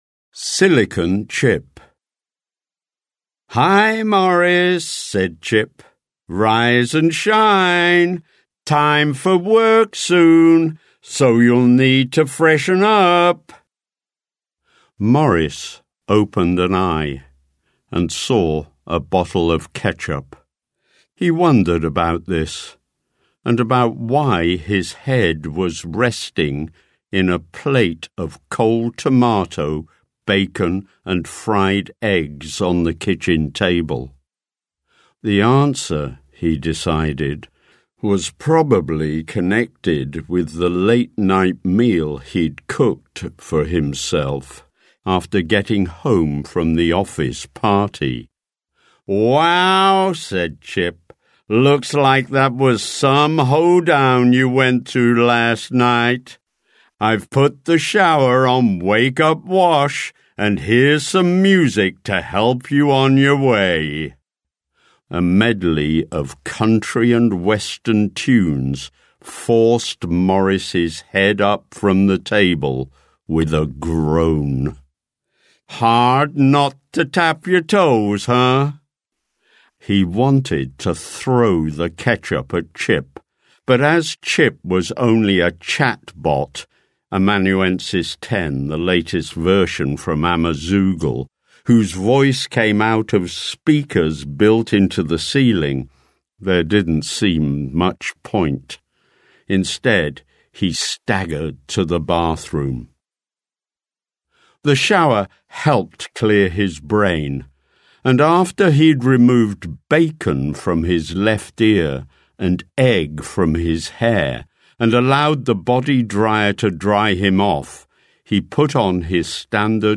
Short Story
Mono-Lingual